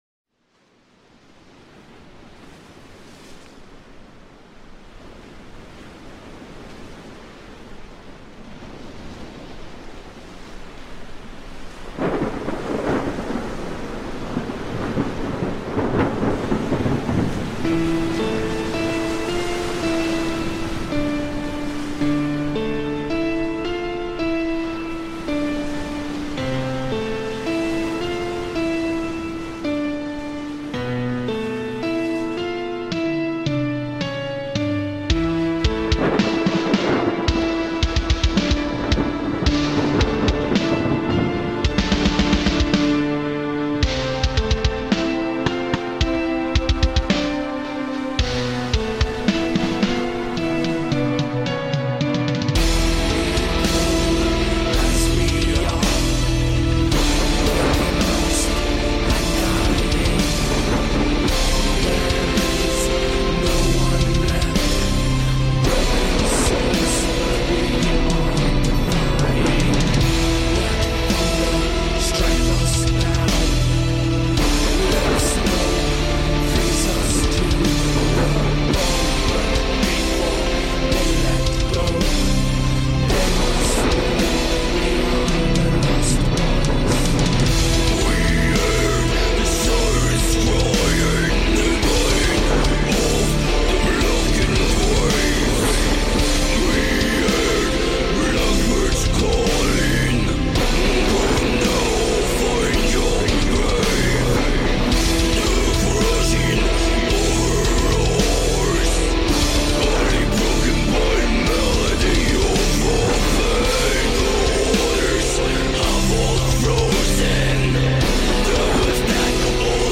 Genre - Doom Metal / Epic Metal